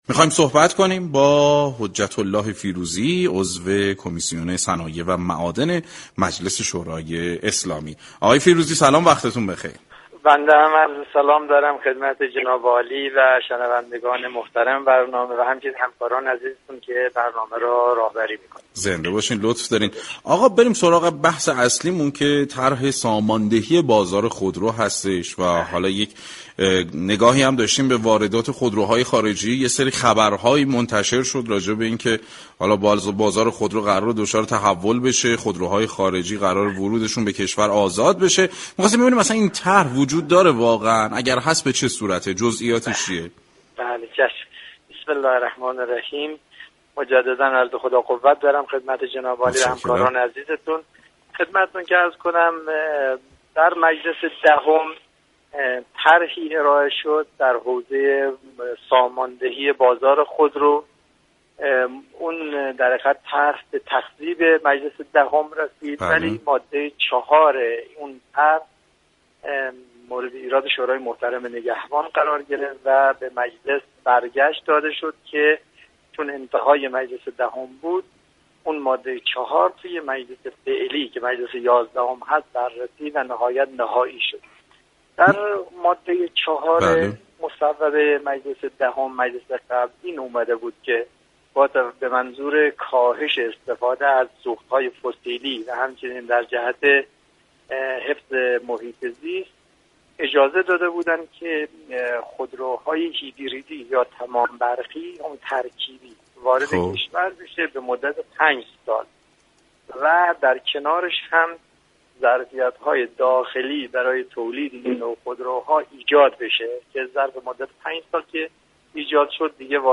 به گزارش پایگاه اطلاع رسانی رادیو تهران، حجت‌الله فیروزی عضو كمیسیون صنایع و معادن مجلس یازدهم در گفتگو با برنامه سعادت‌آباد رادیو تهران درباره طرح جدید مجلس مبنی بر واردات خودروهای خارجی به كشور و تاثیر آن بر بازار خودروهای داخلی گفت: در مجلس دهم طرحی در حوزه ساماندهی بازار خودرو ارائه و تصویب شد اما ماده 4 این طرح با ایراد شورای نگهبان روبرو و به مجلس عودت داده شد تا در مجلس یازدهم دوباره مورد بررسی قرار گرفت و نهایی شد.